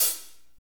HAT F S L0EL.wav